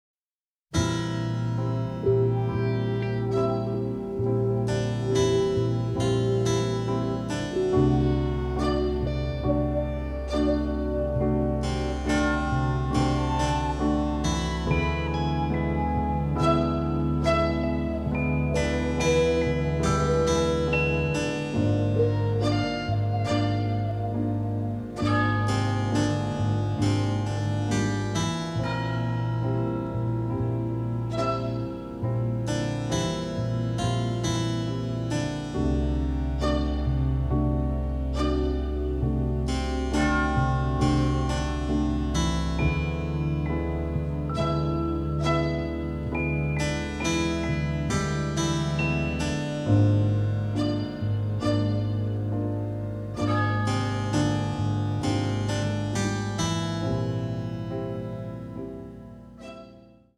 sophisticated avant-garde sound